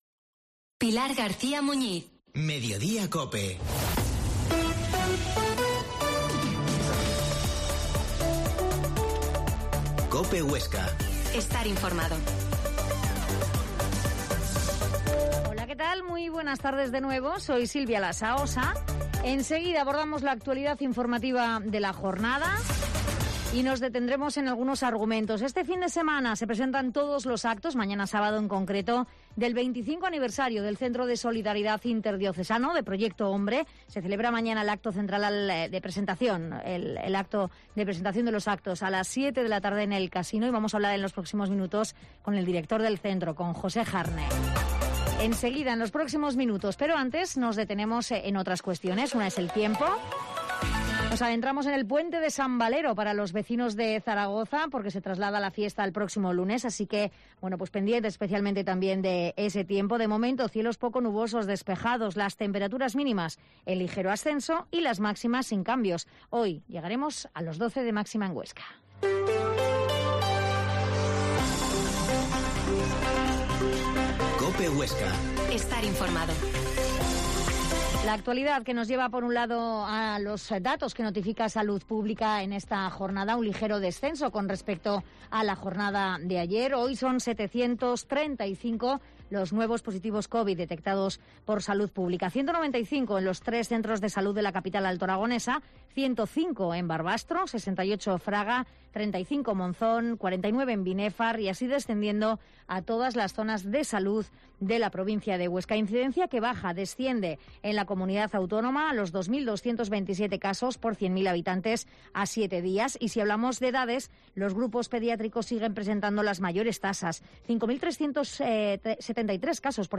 La Mañana en COPE Huesca - Informativo local Mediodía en Cope Huesca 13,50h.